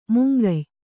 ♪ mungay